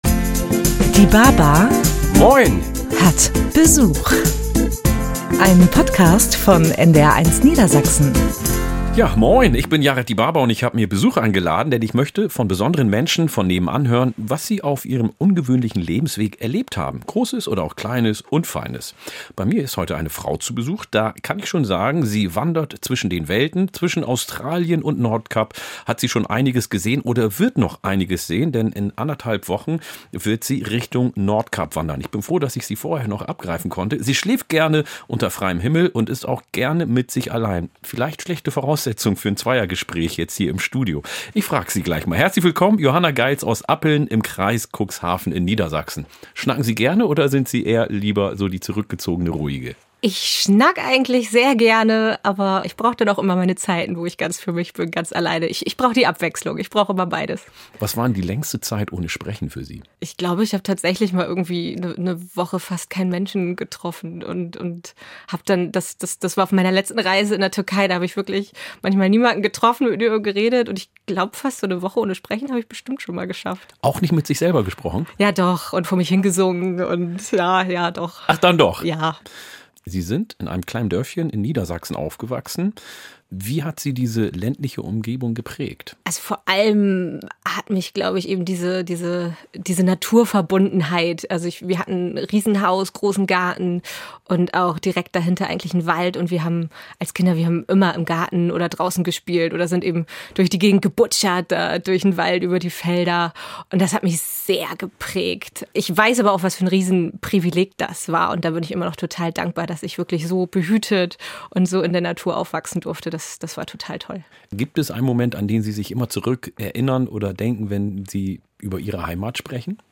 Yared Dibaba schnackt gerne!
Jeden ersten Samstag im Monat lädt er spannende Menschen von nebenan ein und plaudert mit ihnen über Gott und die Welt – und über das, was sie ausmacht.